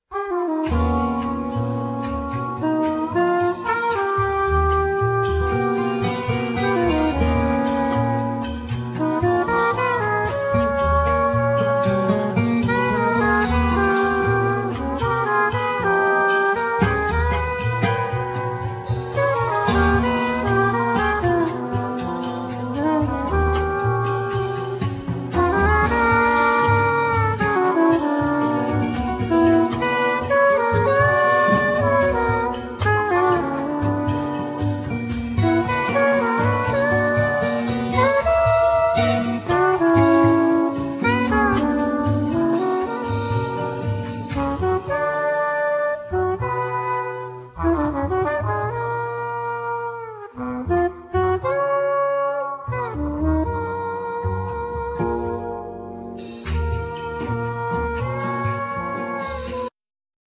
Tenor & Soprano sax
Classical guitars, 12-strings guigtar, Piano
Bass
Drums
Percussions, Vocals
Trombone